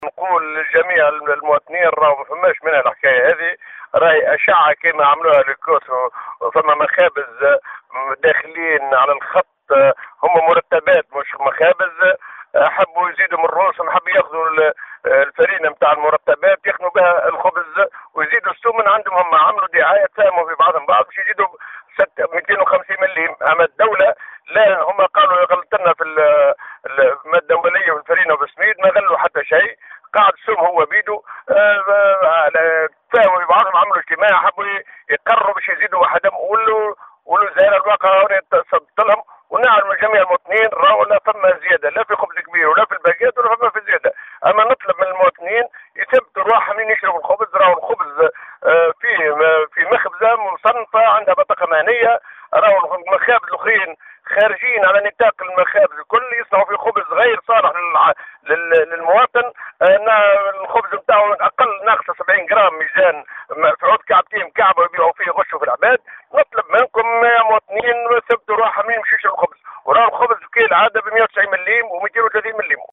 وقال في تصريح اليوم لـ"الجوهرة أف أم"، إن كل ما تم ترويجه في هذا الخصوص هو "مجرّد إشاعات".